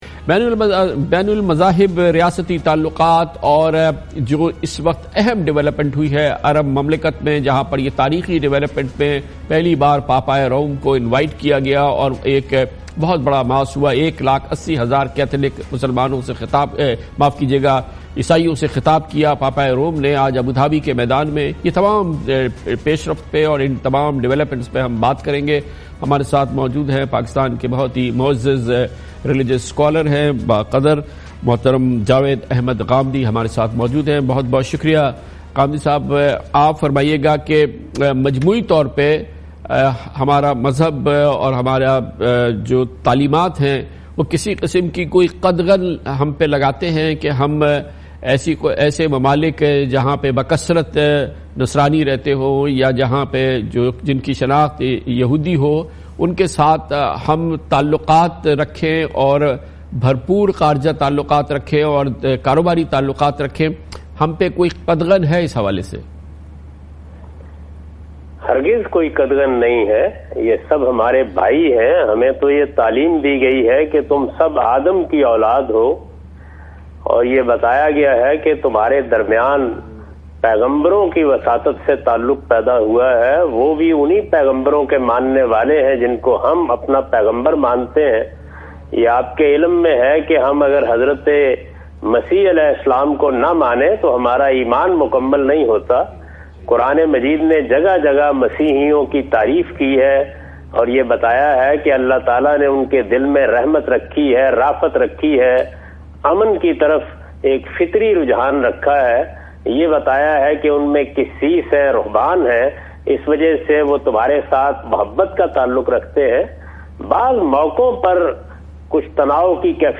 Category: TV Programs / Dunya News / Questions_Answers /
Javed Ahmad Ghamidi speaks about Pop Francis Vists UAE FEB 2019 on Dunya News.